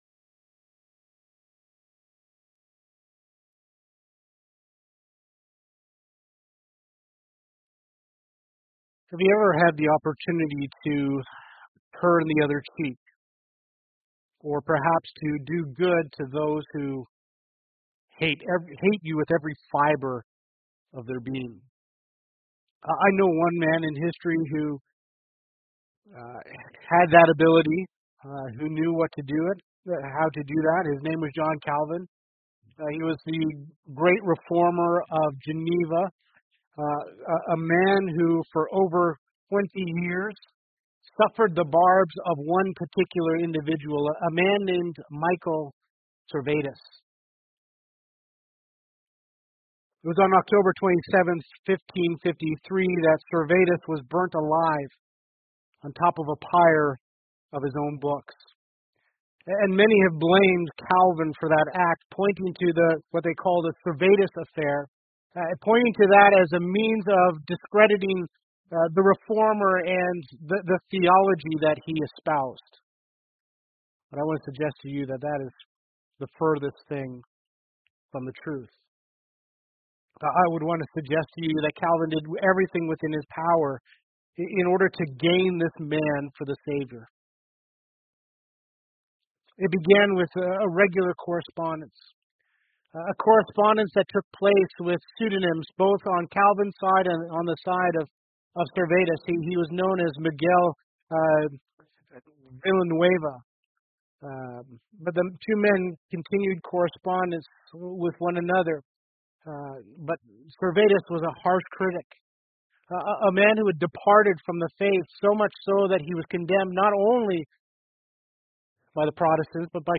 Sunday Sermon Series